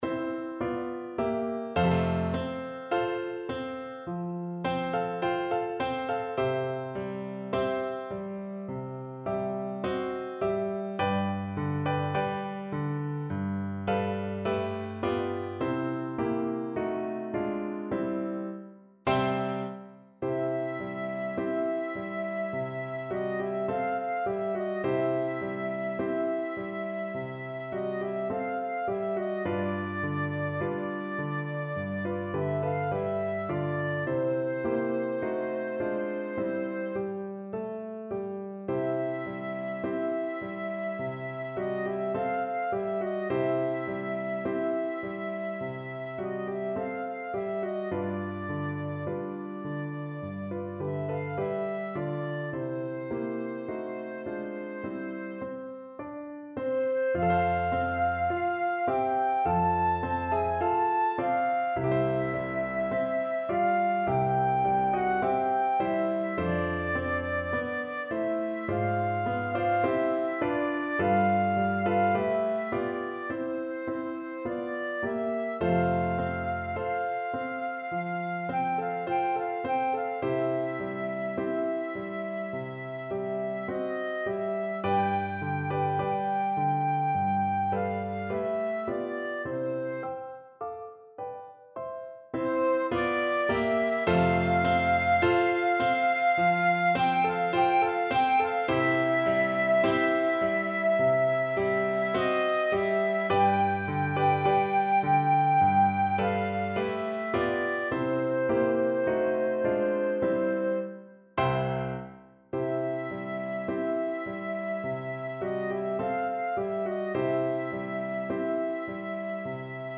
2/4 (View more 2/4 Music)
Andantino =c.52 (View more music marked Andantino)
C6-A6
Classical (View more Classical Flute Music)